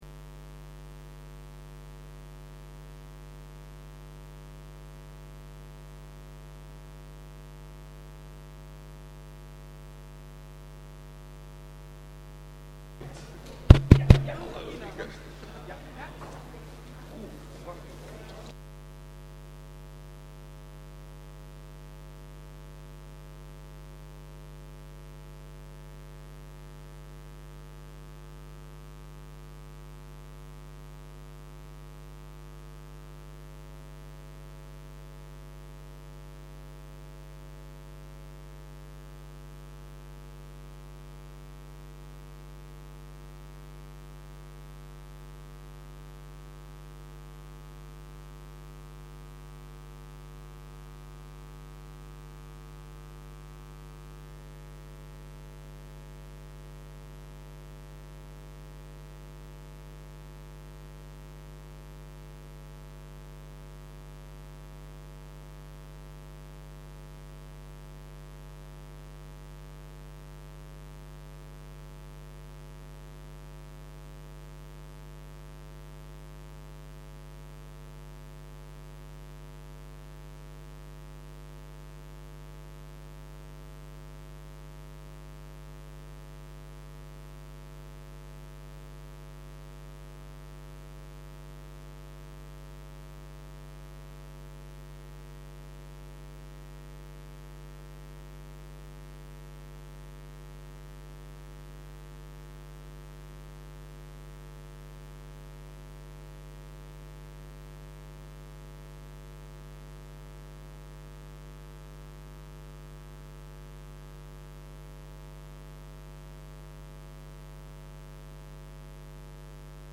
Raadsvergadering
Locatie: Raadzaal